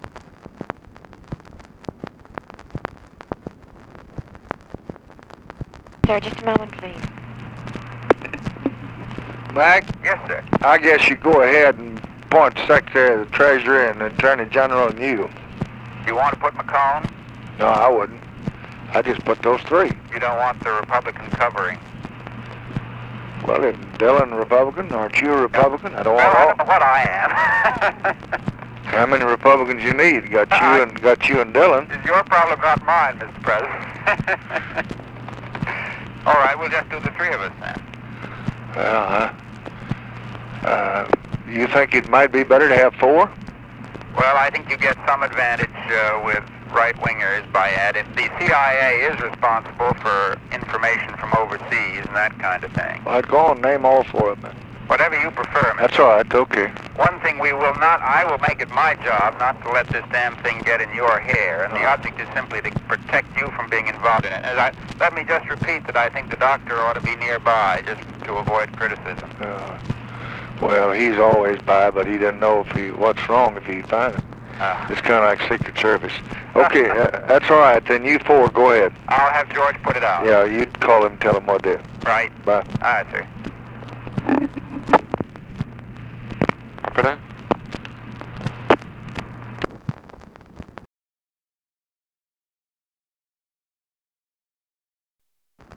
Conversation with MCGEORGE BUNDY, September 27, 1964
Secret White House Tapes